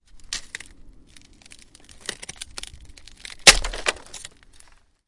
木栅栏的声音 " 啪啪, 木栅栏, F
Tag: 栅栏 开裂 裂缝 开裂 断裂 木材 木方 木板 栅栏 折断 开裂 捕捉 围栏 围栏 打破